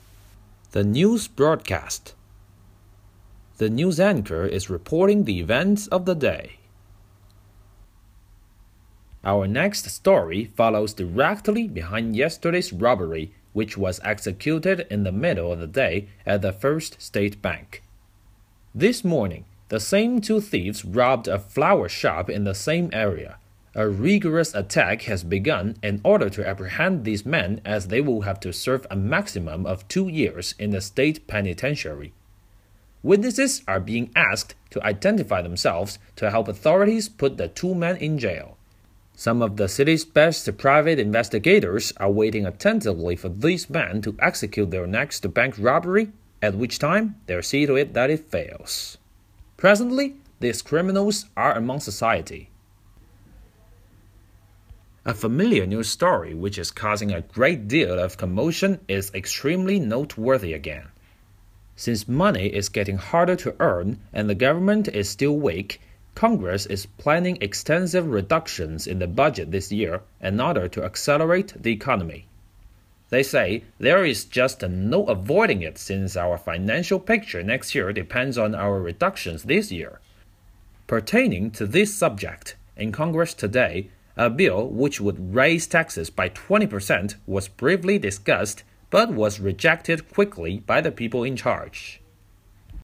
The News Broadcast
The news anchor is reporting the events of the day.